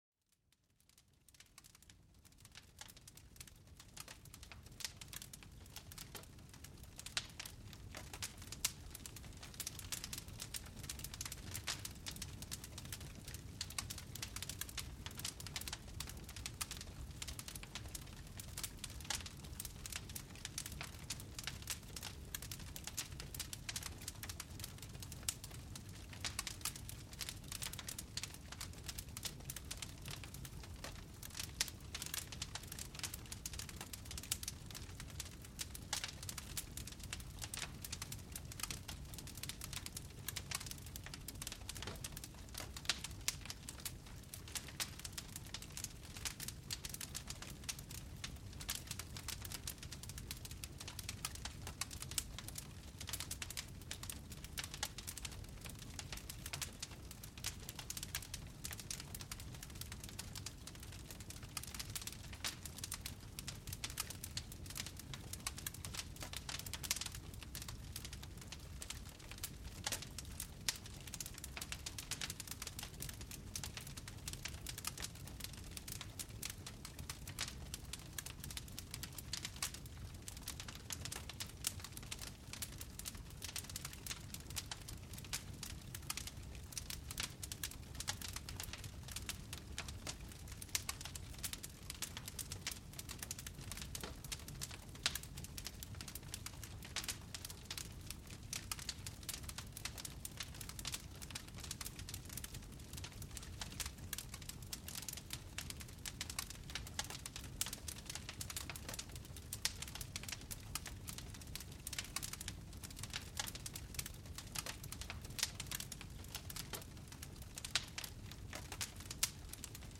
Crépitement du feu : Plongez dans une chaleur apaisante